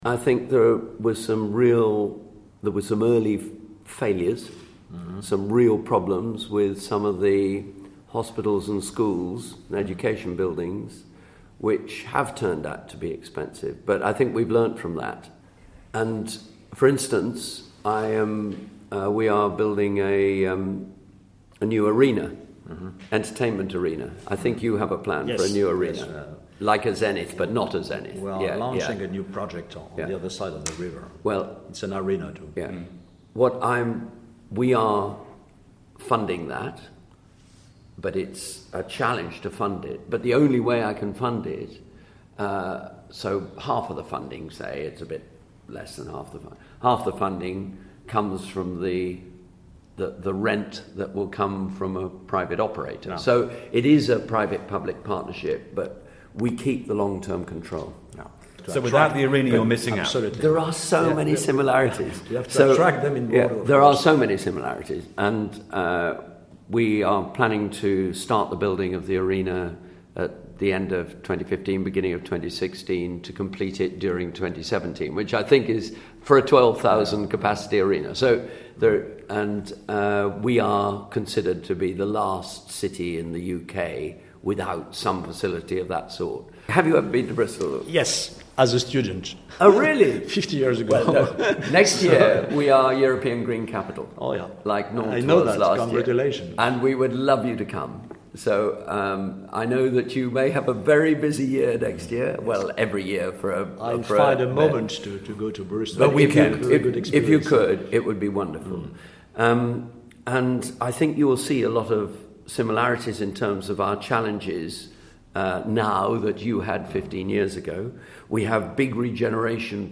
Second part of George Ferguson and his first ever meeting with Bordeaux's Mayor - Alain Juppe. In this second interview - Alain Juppe started off by asking his Bristol counterpart - whether or not Bristol would consider using private money to finance projects.. instead of public cash.